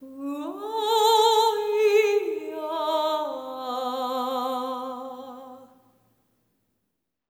ETHEREAL04-L.wav